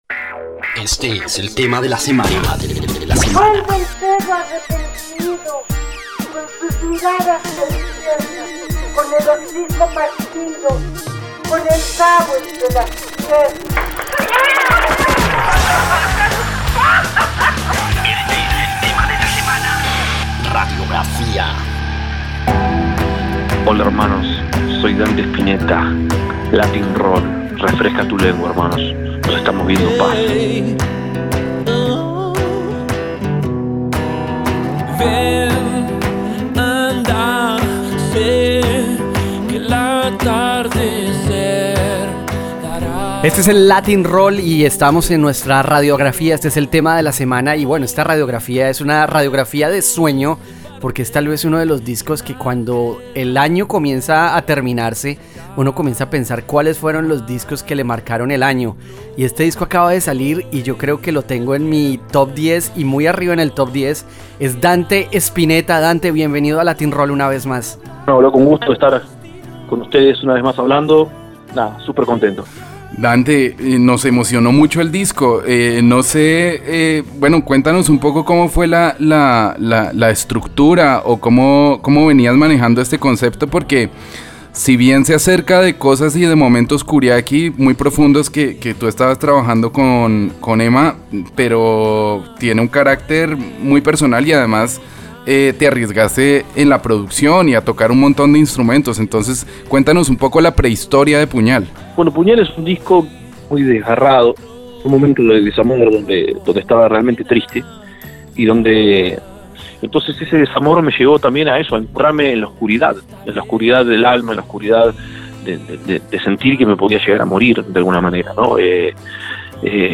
Entrevista exclusiva en donde destapamos el nuevo trabajo del compositor argentino, 13 tracks llenos de emoción y profundidad sonora.